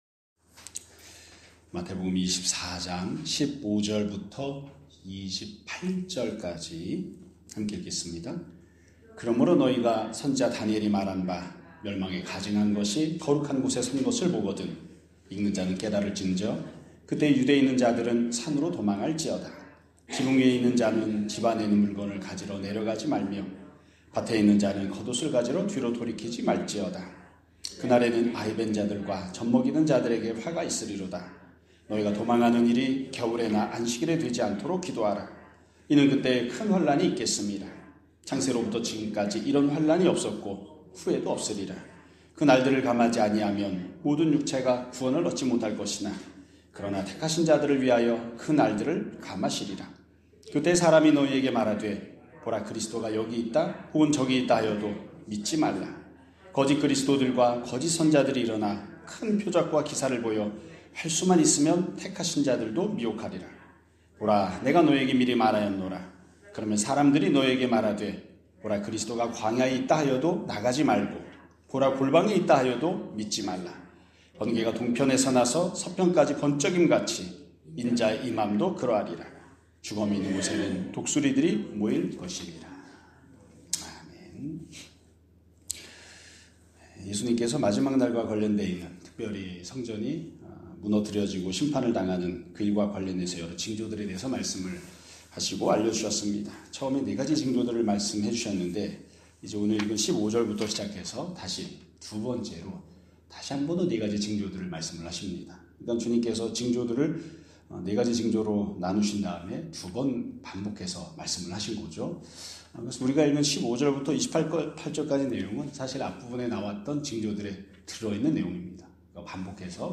2026년 3월 11일 (수요일) <아침예배> 설교입니다.